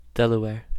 Ääntäminen
Synonyymit Lenape Ääntäminen US UK : IPA : /ˈdɛləweə(ɹ)/ US : IPA : /ˈdɛləwɛɹ/ Lyhenteet ja supistumat Del Haettu sana löytyi näillä lähdekielillä: englanti Käännöksiä ei löytynyt valitulle kohdekielelle.